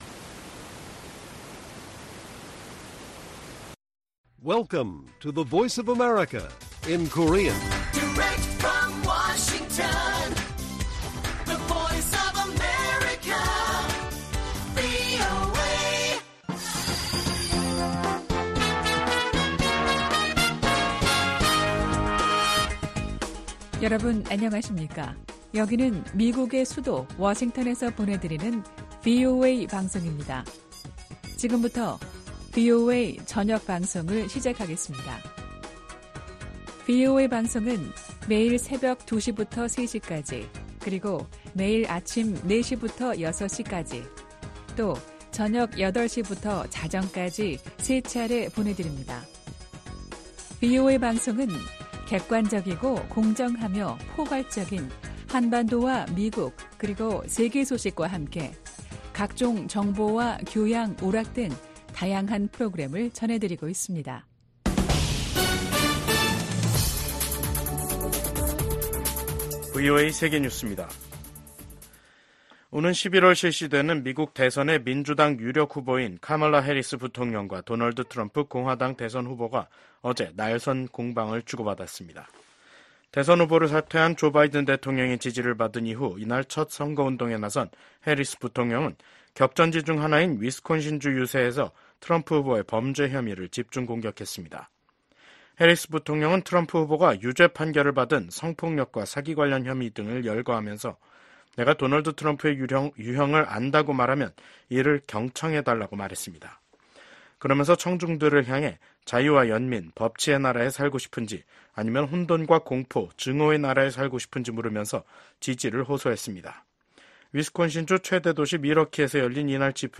VOA 한국어 간판 뉴스 프로그램 '뉴스 투데이', 2024년 7월 24일 1부 방송입니다. 북한이 또 다시 쓰레기 풍선을 한국 쪽에 날려보냈습니다.